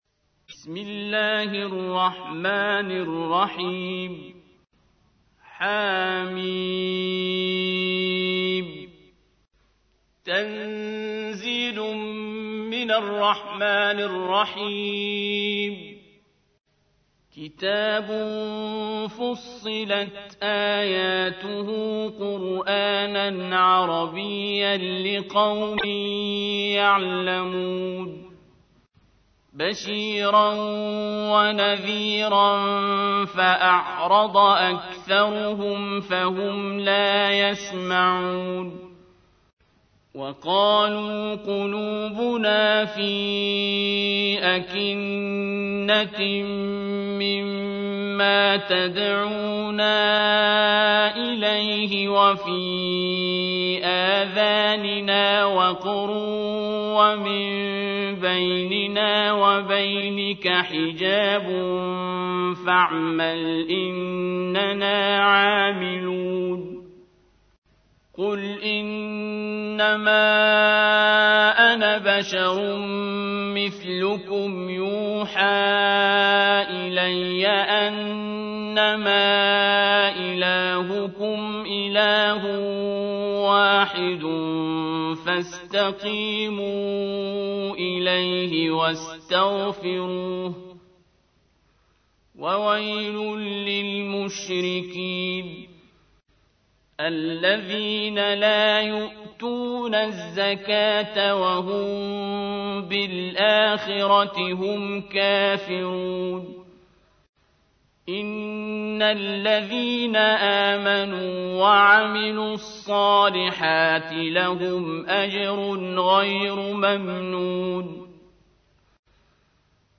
تحميل : 41. سورة فصلت / القارئ عبد الباسط عبد الصمد / القرآن الكريم / موقع يا حسين